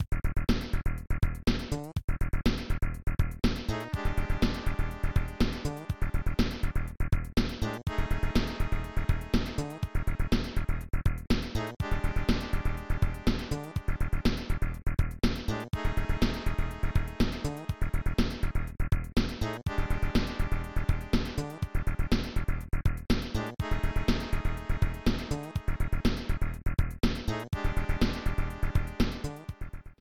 BGM